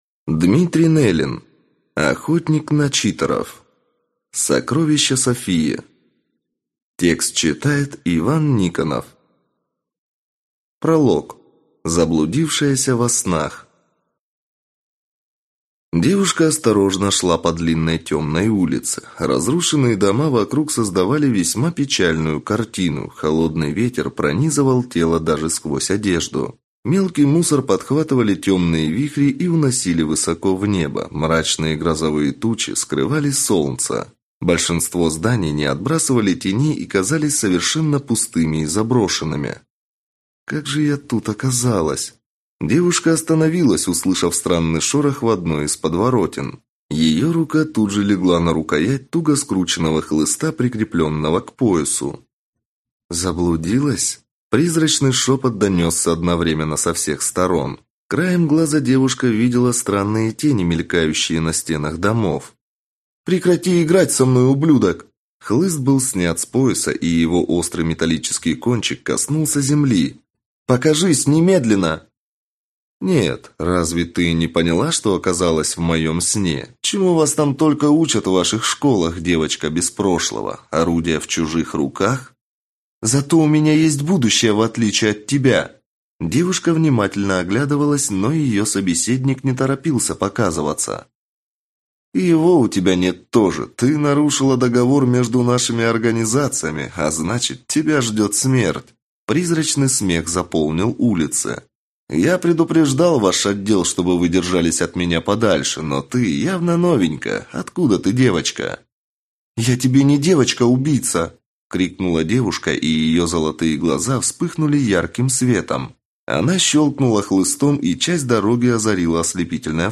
Аудиокнига Сокровище Софии | Библиотека аудиокниг